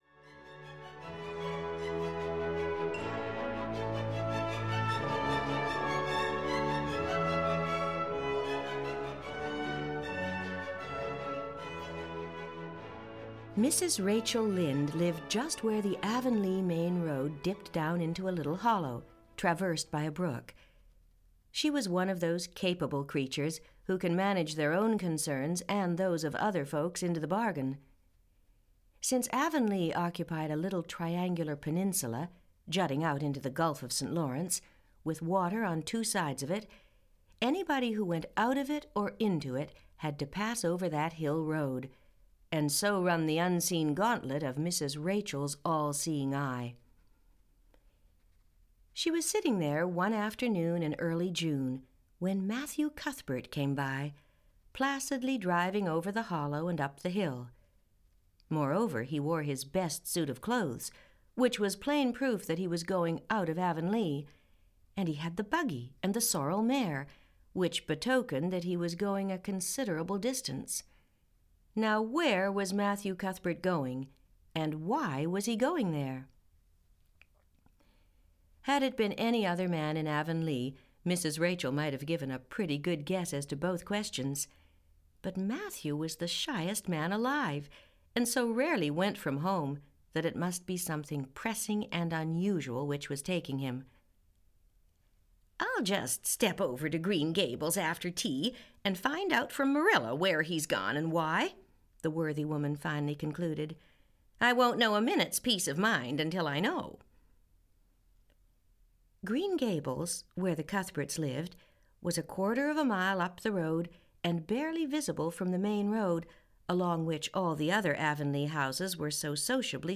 Anne of Green Gables (EN) audiokniha
Ukázka z knihy